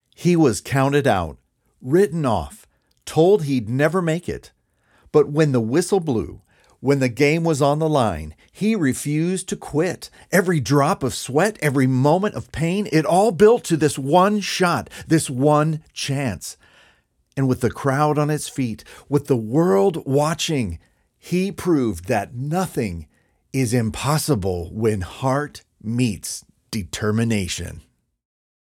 Documentary Drama
North American English, British (general)
- Professional recording studio and analog-modeling gear